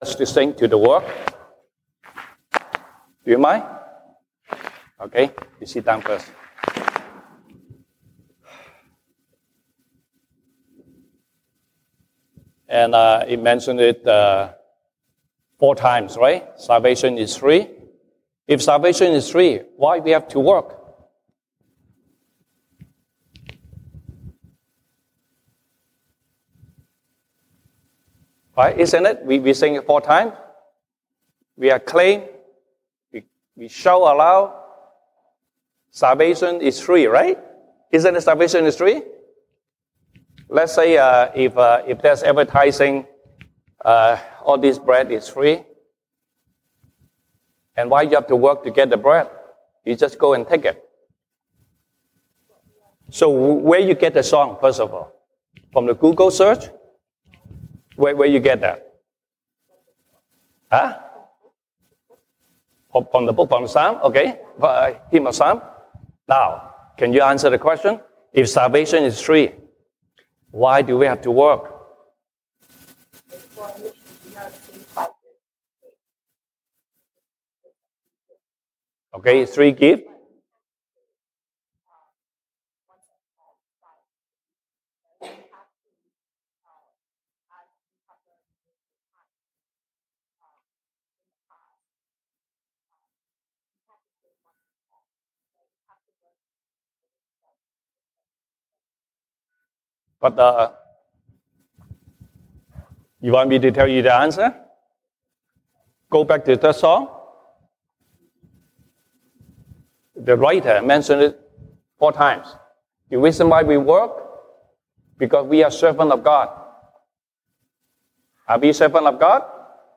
西堂證道 (英語) Sunday Service English: God loves a cheerful giver
Passage: 歌林多後書 2 Corinthians 9:1-15 Service Type: 西堂證道 (英語) Sunday Service English